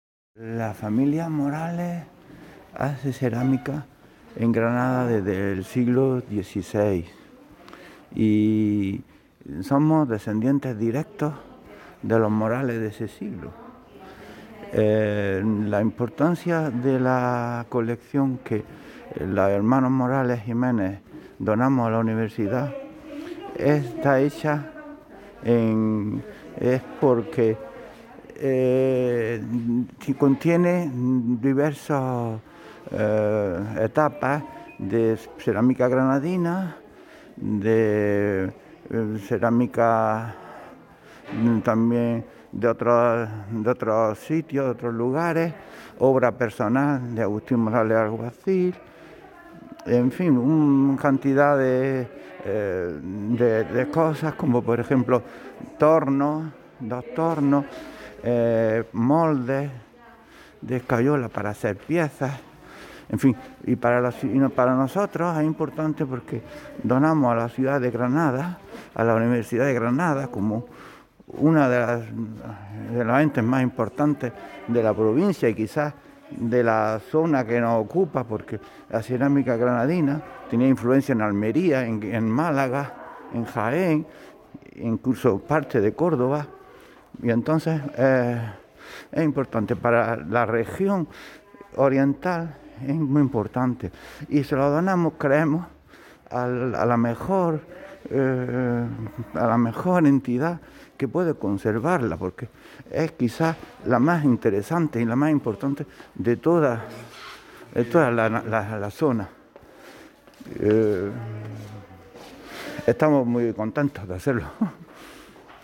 Audios de la firma del convenio: